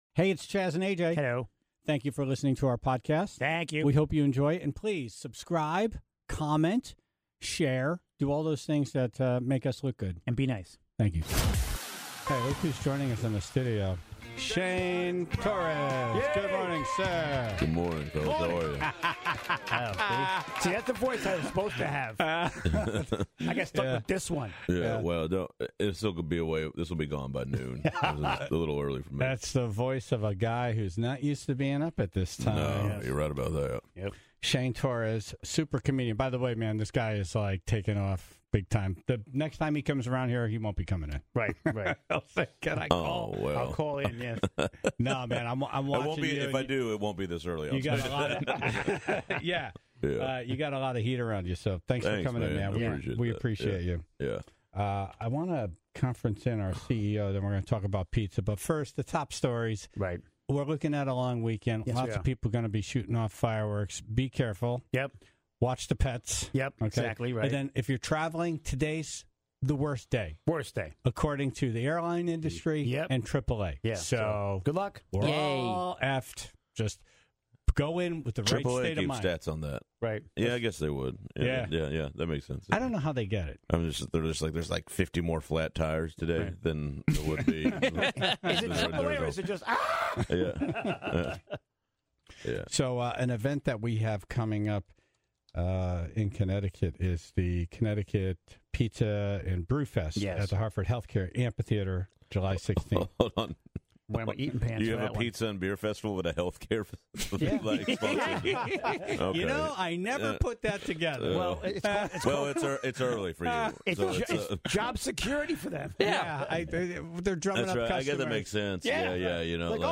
(19:42) Dumb Ass News - The Flubble Montage! Enjoy a week's worth of flubs, mumbles, screw-ups and fails.